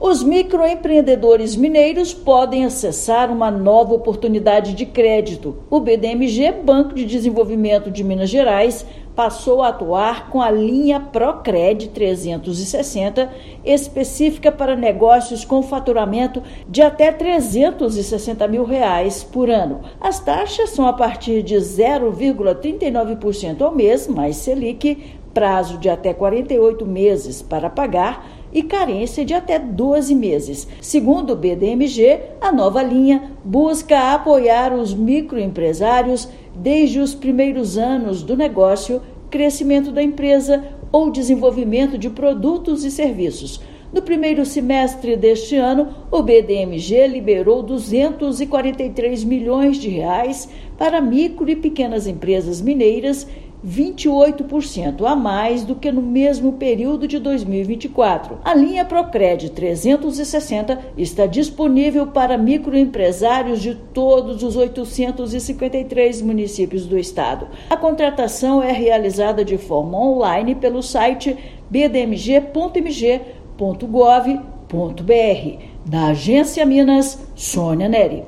Produto ProCred 360 permite que negócios de menor porte em Minas Gerais sejam financiados com taxas ainda mais acessíveis. Ouça matéria de rádio.